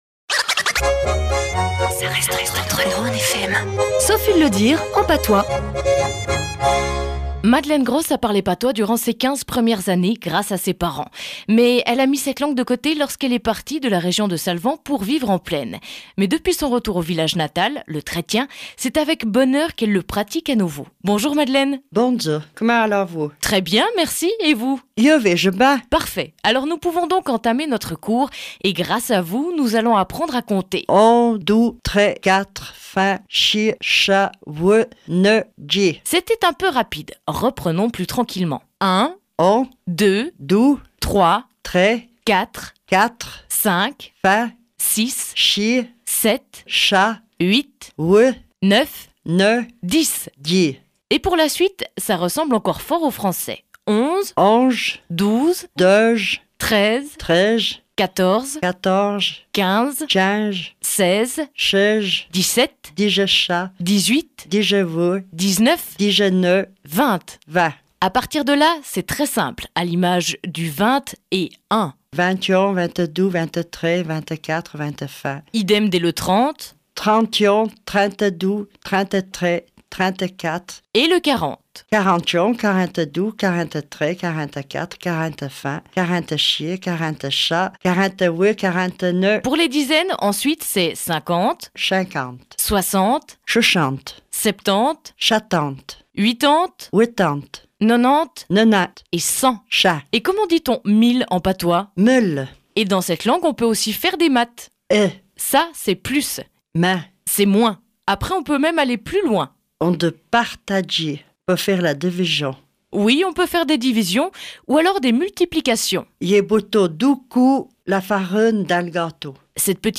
Le programmo de radio que l’at difusâ cen l’est : -"Ca reste entre nous !" (cliquar por vêre)
Trétien (pas louèn de Salvan)